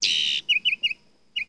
mockbird.wav